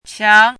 “疆”读音
qiáng
qiáng.mp3